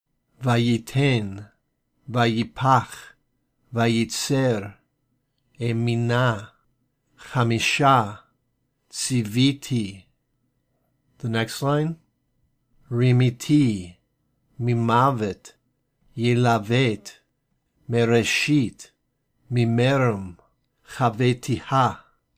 • Although these vowel marks look different, they both represent an "ee" sound.
Three / more syllables:
Practice Readings